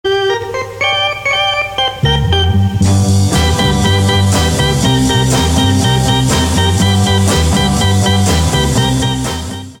• Качество: 193, Stereo
громкие
без слов
инструментальные
blues Rock
психоделический рок
garage rock